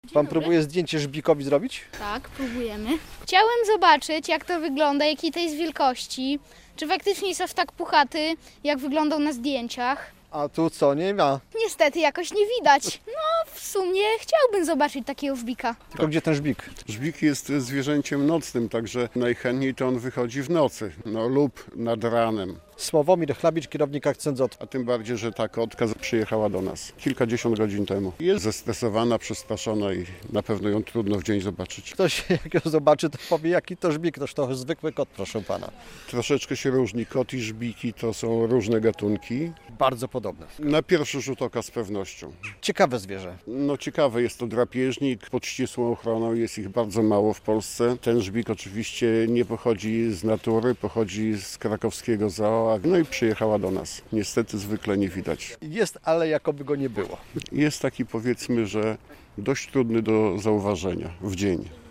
Nowy mieszkaniec Akcentu ZOO - relacja